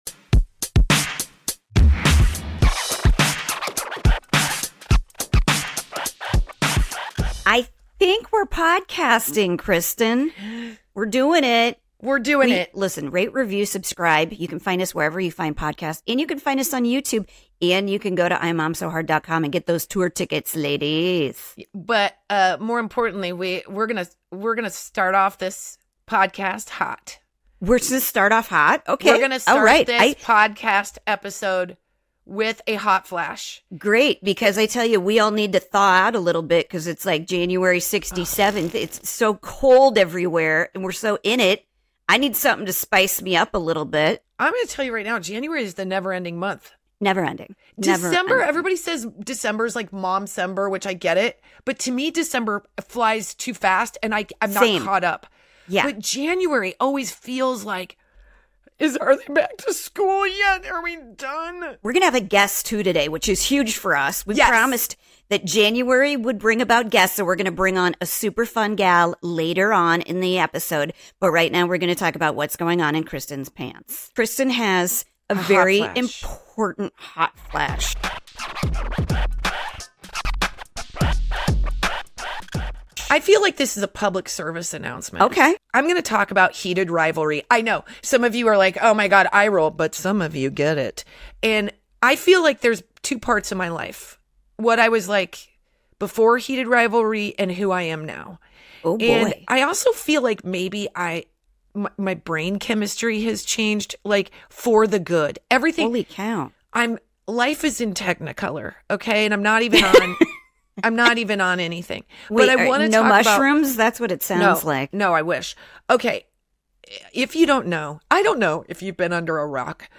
Female comedy duo